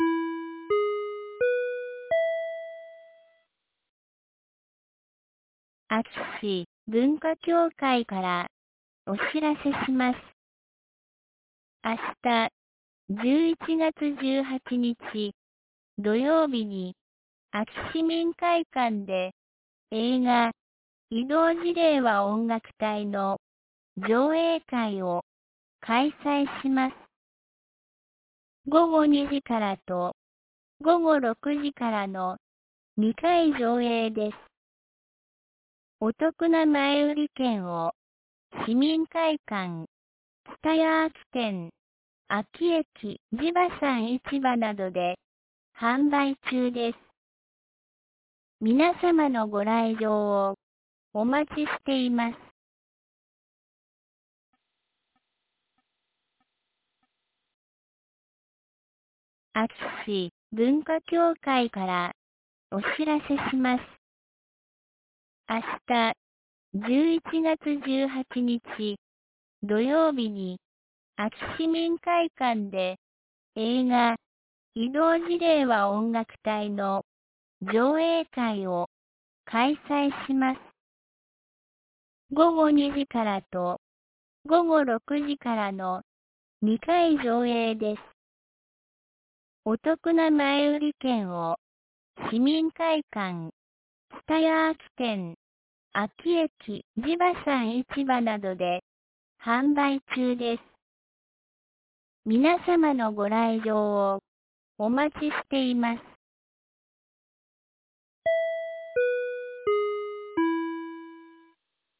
2023年11月17日 17時11分に、安芸市より全地区へ放送がありました。